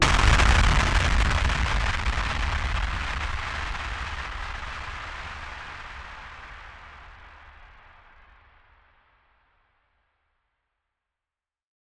BF_DrumBombB-10.wav